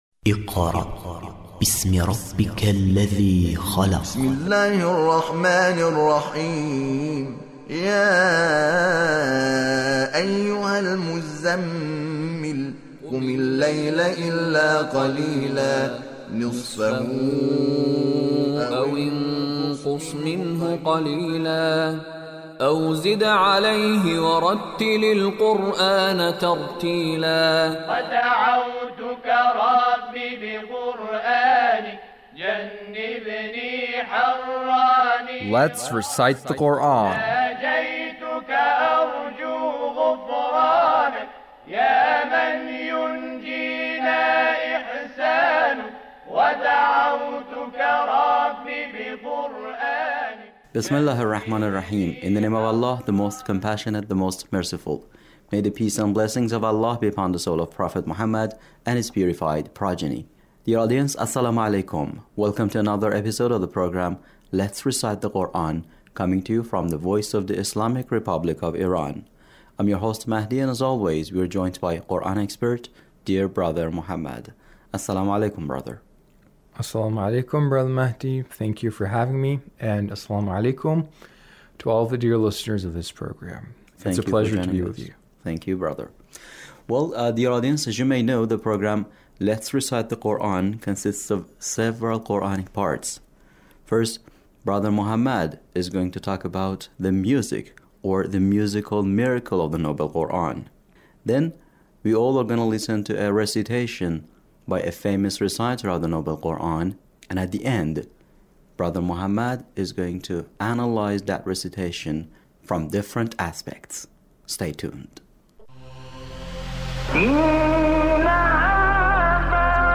Sayyed Mutawalli Abdul Aal recitation